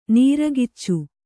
♪ nīragiccu